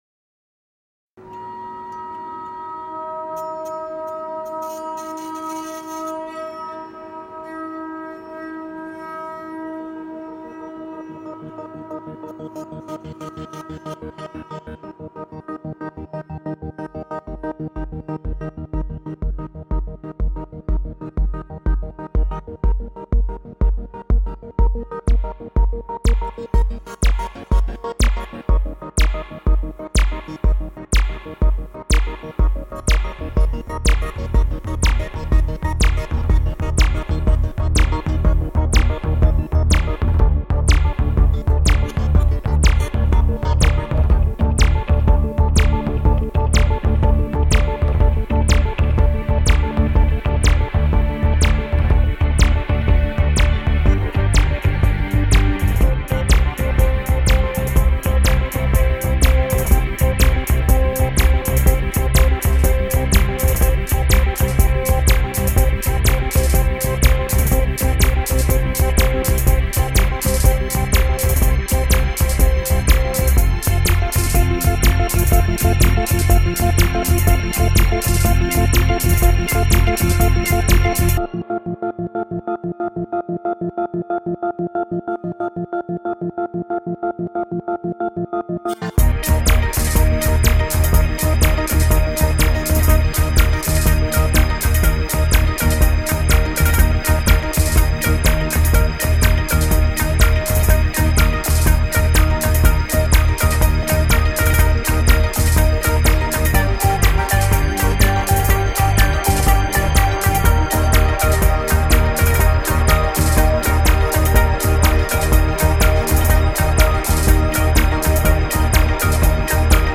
Saint Petersburg art exhibition